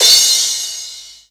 • Drum Crash C# Key 11.wav
Royality free crash cymbal drum sound tuned to the C# note. Loudest frequency: 5162Hz
drum-crash-c-sharp-key-11-sg1.wav